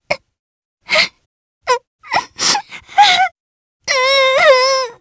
Selene-Vox_Sad_jp.wav